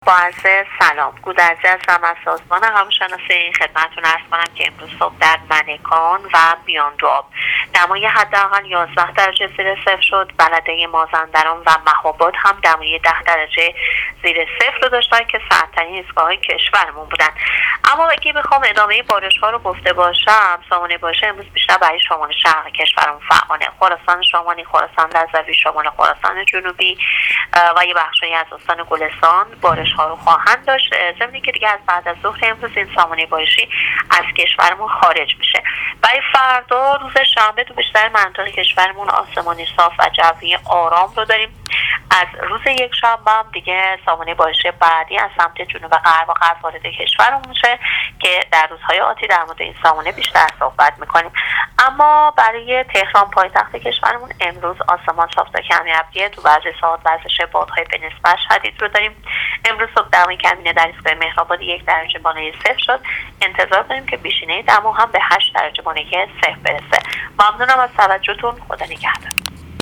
رادیو اینترنتی پایگاه خبری وزارت راه و شهرسازی: